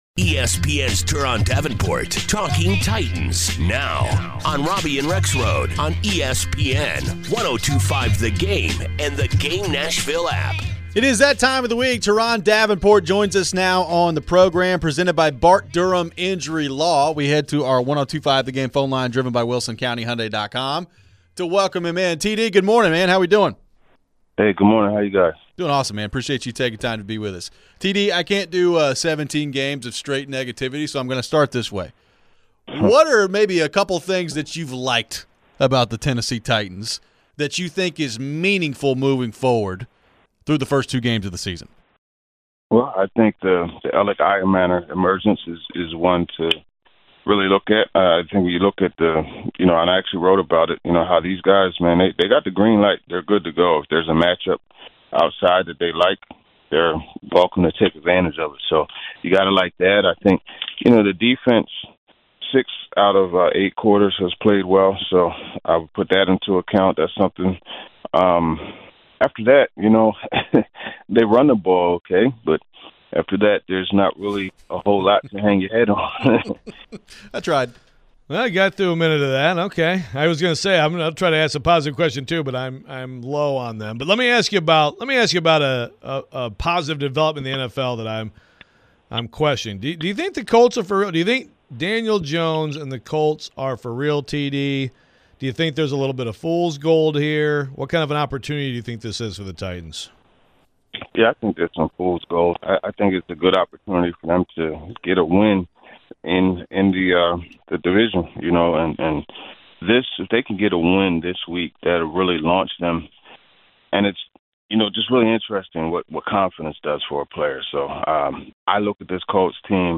We get back to your phones and get some predictions for the game. We have our Pig Skin Pick 'Em segment as we pick some games this weekend.